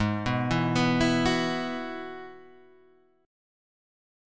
G#m7#5 Chord